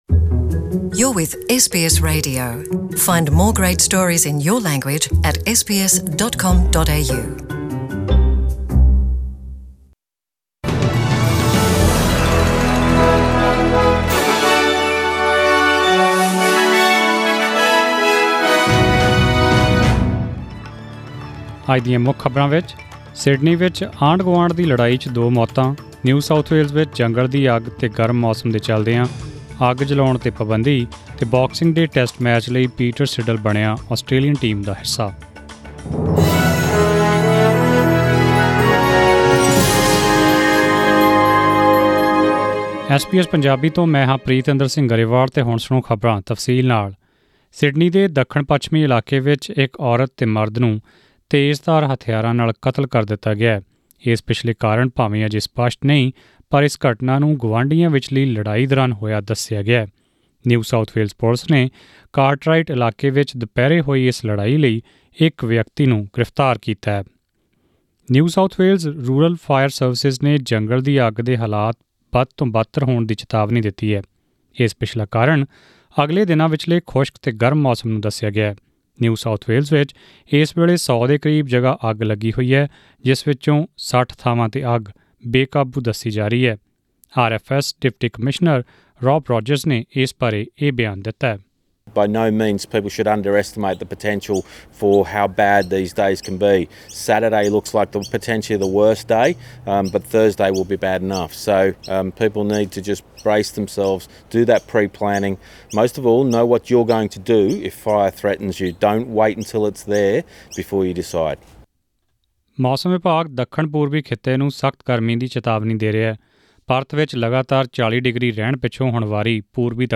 SBS Punjabi News